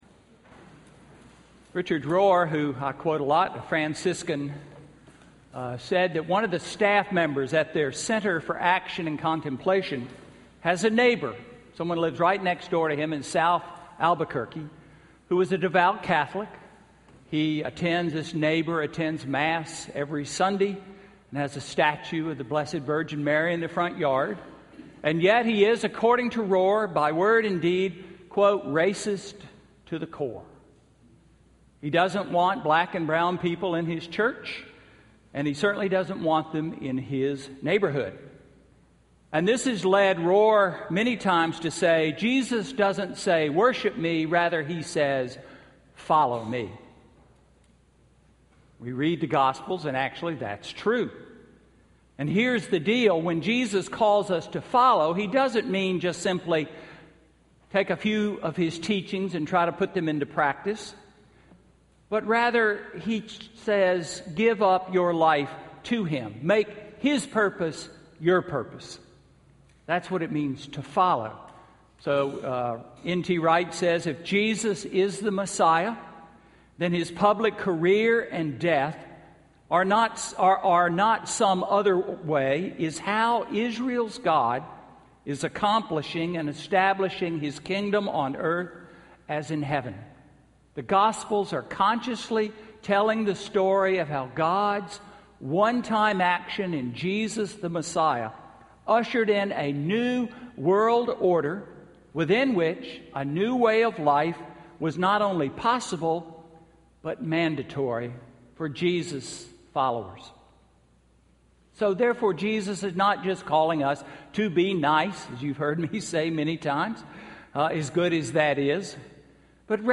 Sermon–August 24, 2014